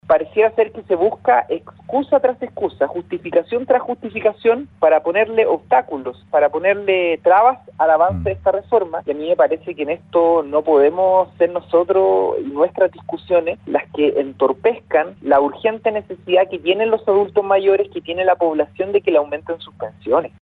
Sin embargo, el presidente de la Comisión de Trabajo, el socialista Juan Santana, en conversación con Radio Bío Bío, afirmó que la posición de la UDI sigue entorpeciendo temas que son de suma urgencia para el país.